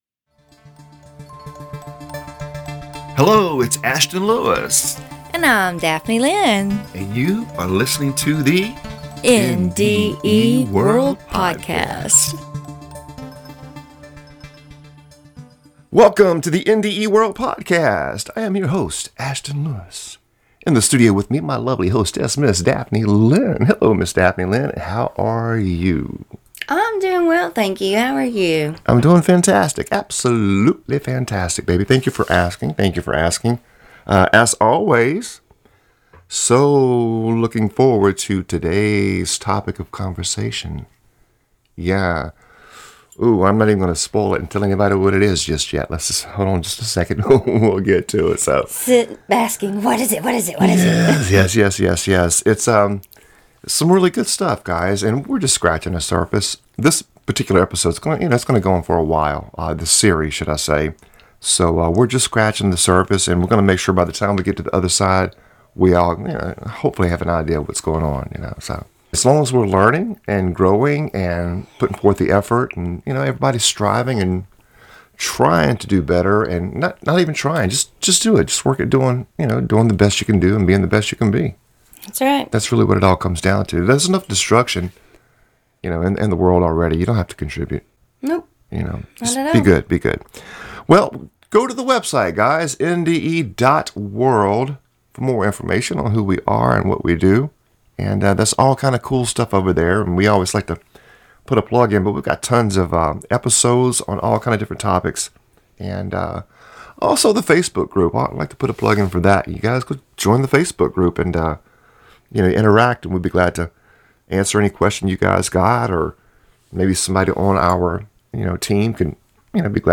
In this series, we will share the world of UDO pronounced (yoo' dō), with our listeners.